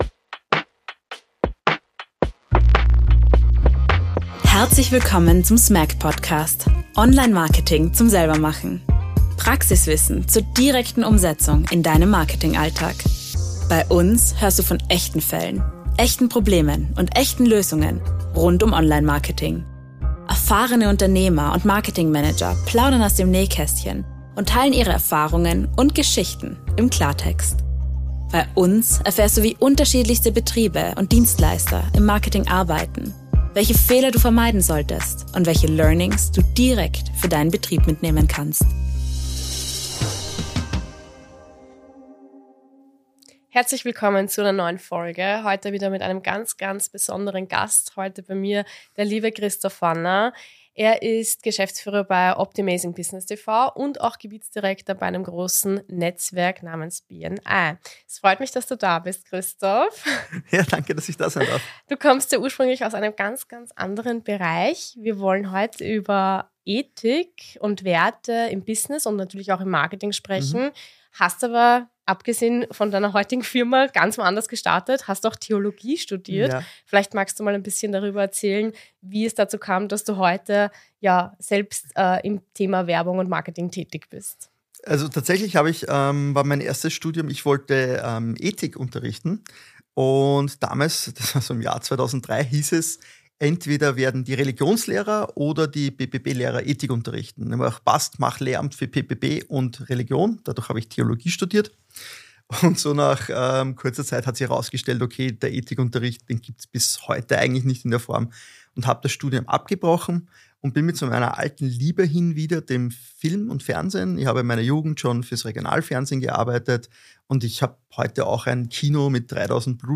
Im Gespräch beleuchtet er, wie Werte und Ethik nicht nur das Innenleben eines Unternehmens formen, sondern auch maßgeblich die Außenwirkung und die Wahl der Kunden beeinflussen.